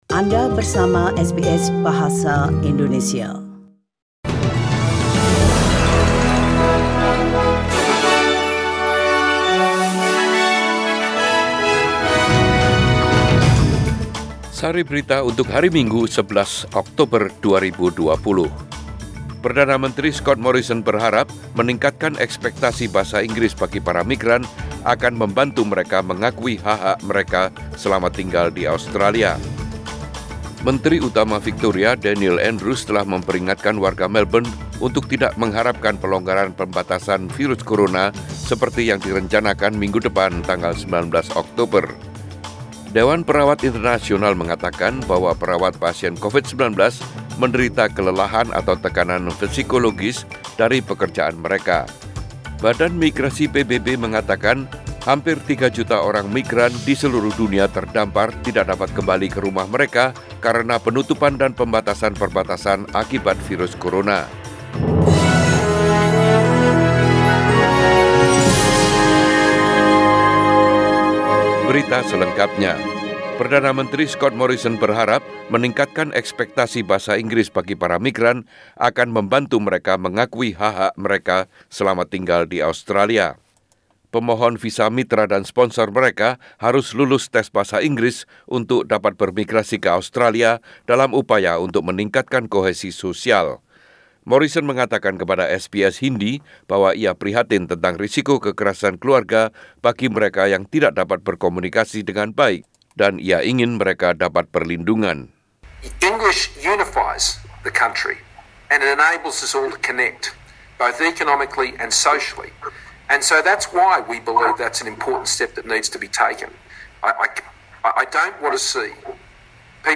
Warta Berita Radio SBS Program Bahasa Indonesia - 11 Oktober 2020